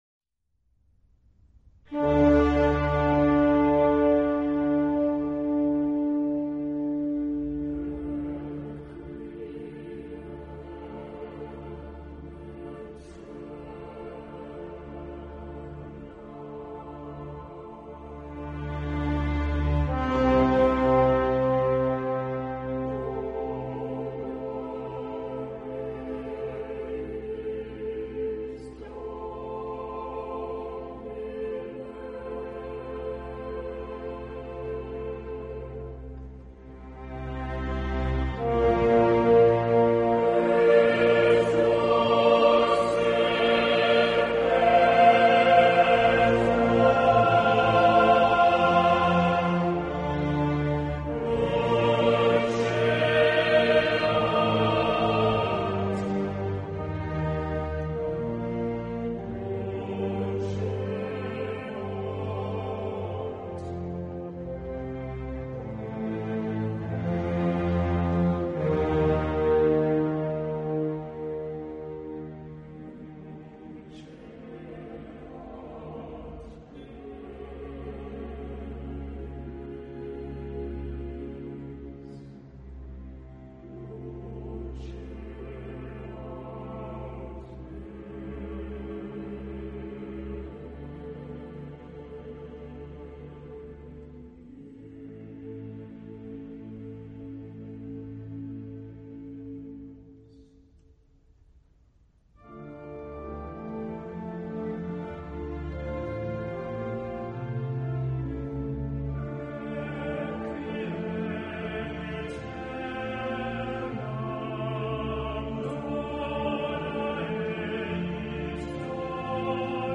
音樂類型：古典音樂
錄音地點在劍橋聖約翰學院大教堂
音效已達最高水準，空間感極佳，層次清晰，溫潤豐沛，是演錄具鞋的經典名演。